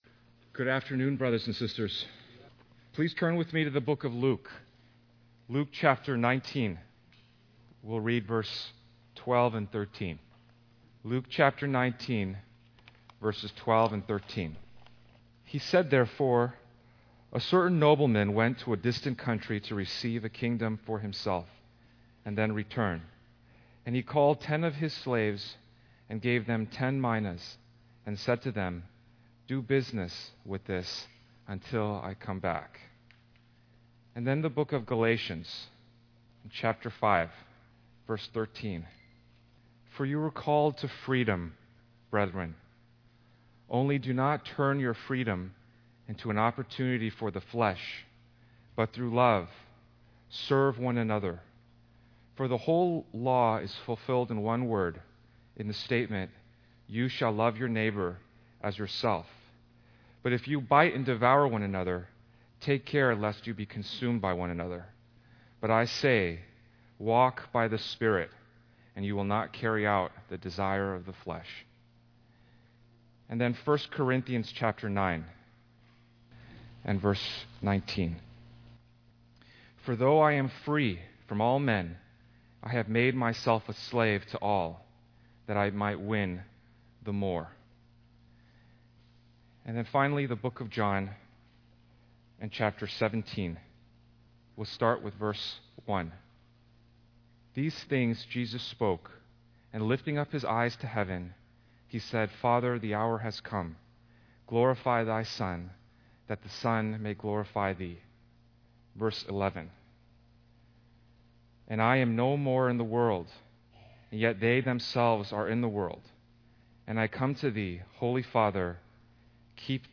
A collection of Christ focused messages published by the Christian Testimony Ministry in Richmond, VA.
Harvey Cedars Conference We apologize for the poor quality audio